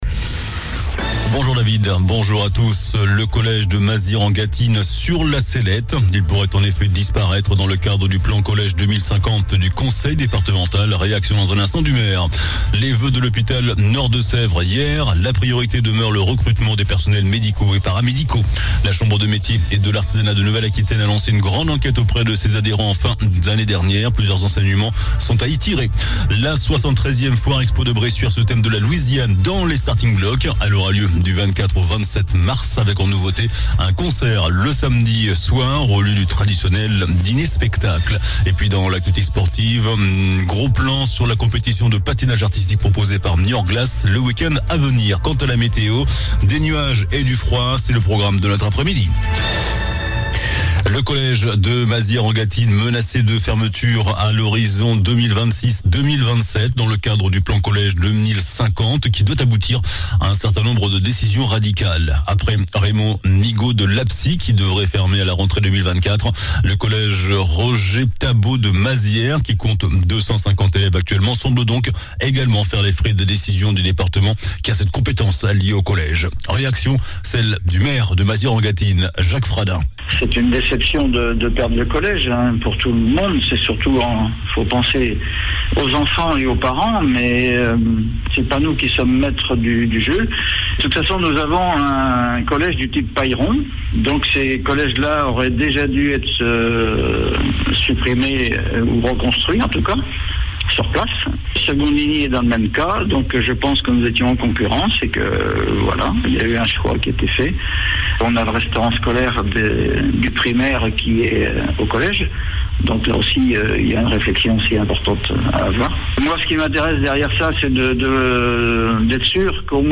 JOURNAL DU JEUDI 26 JANVIER ( MIDI )